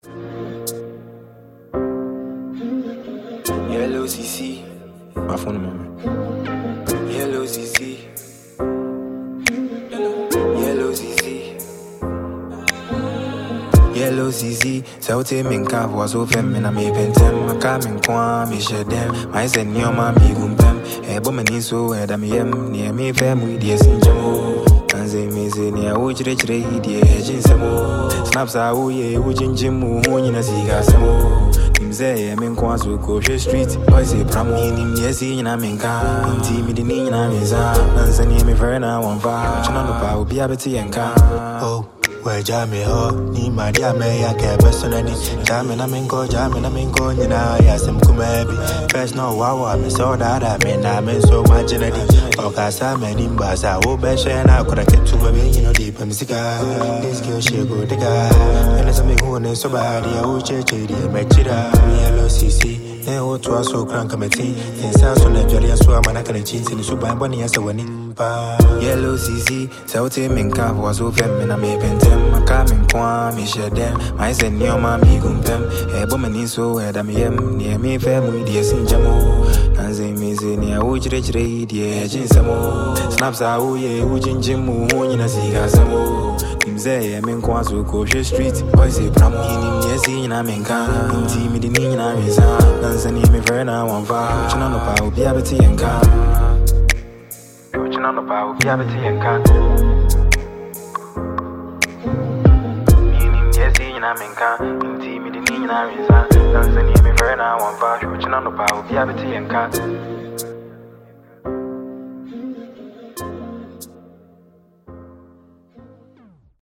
Ghana
With its street feel and lively rhythm